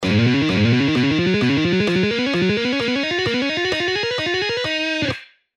Legato Guitar Exercise
Lessons-Guitar-Mark-Tremonti-Legato-Exercises-5.mp3